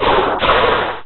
Cri de Cacturne dans Pokémon Rubis et Saphir.